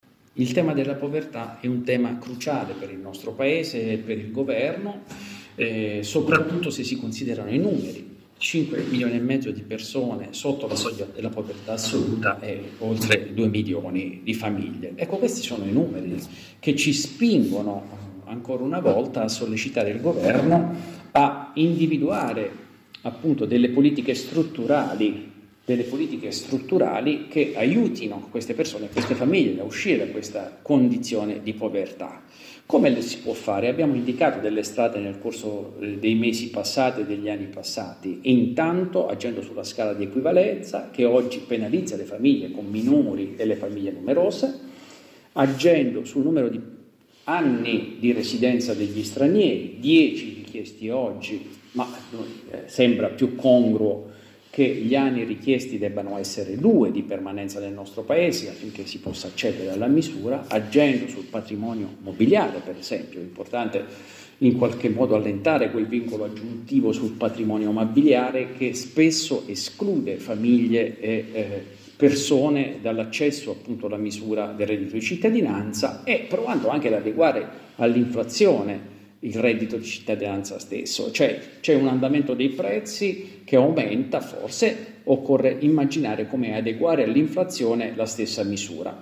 Bentornati all’ascolto del Grs Week.